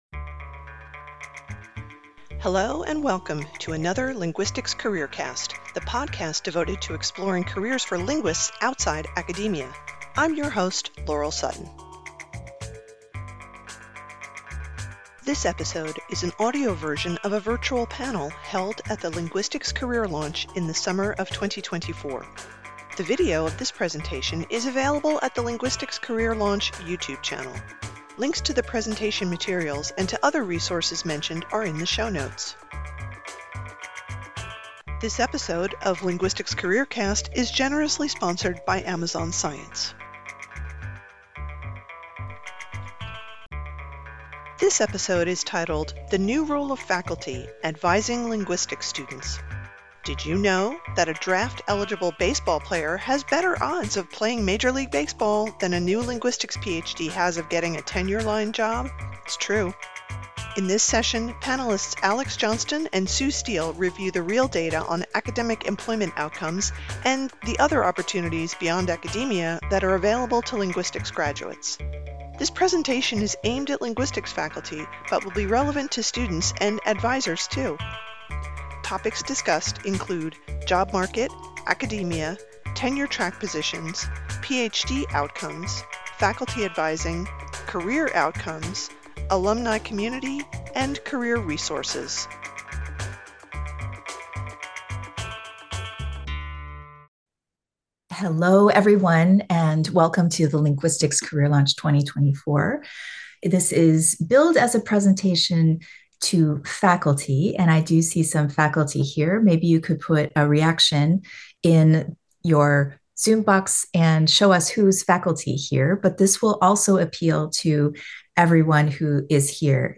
This presentation is aimed at linguistics faculty, but will be relevant to students and advisors too!